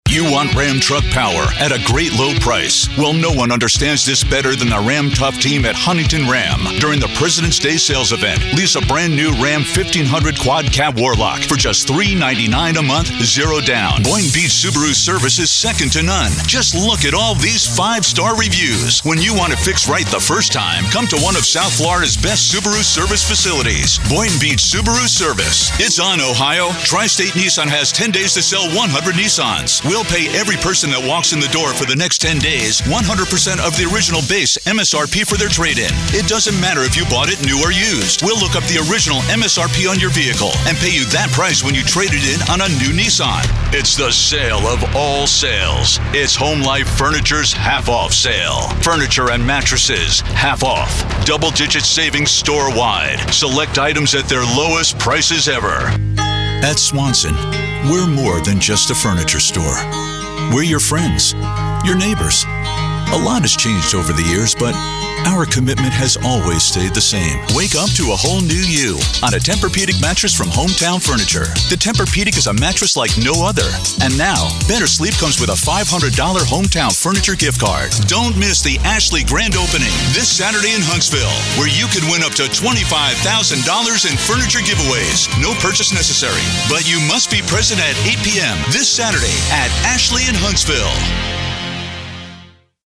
Male
Adult (30-50), Older Sound (50+)
His voice is like warm butter, smooth, professional, and articulate, yet approachable.
Television Spots
Words that describe my voice are Warm, Authoritative, Approachable.
0319Retail_Auto_Demo.mp3